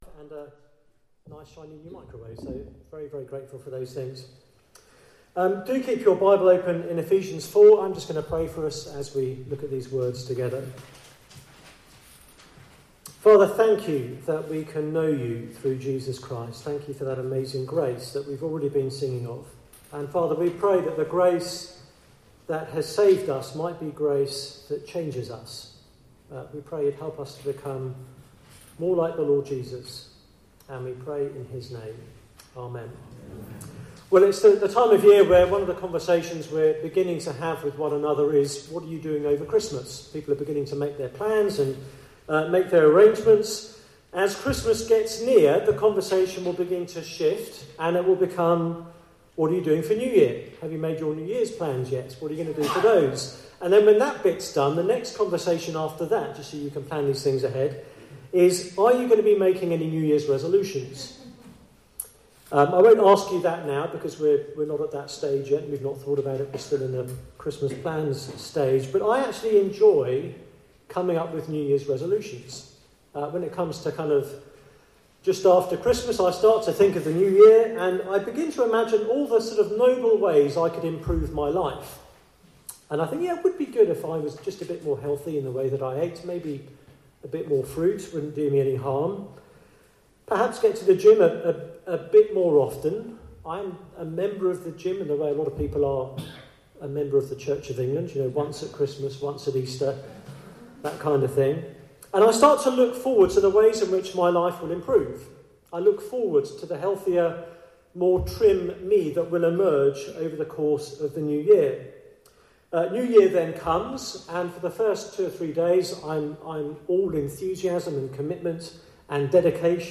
Passage: Ephesians 4:17-32 Service Type: Weekly Service at 4pm Bible Text